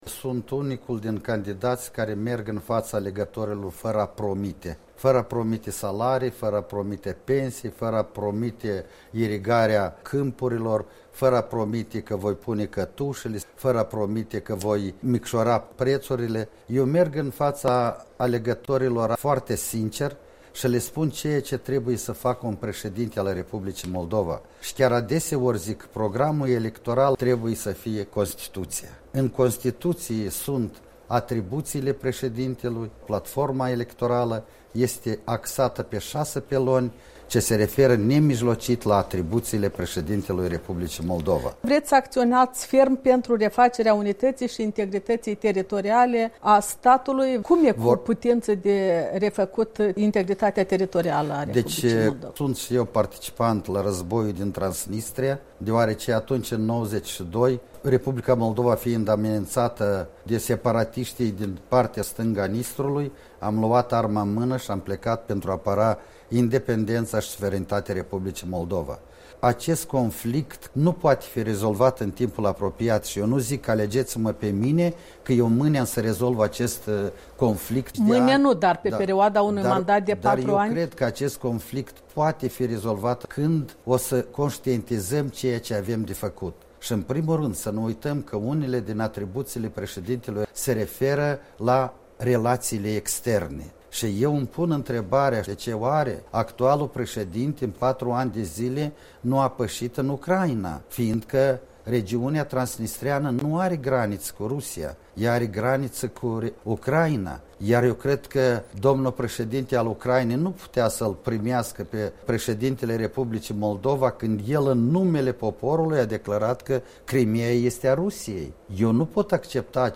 Interviul electoral al Europei Libere cu candidatul PLDM la prezidențiale, Tudor Deliu.
Interviu electoral cu Tudor Deliu, candidatul PLDM în alegerile prezidențiale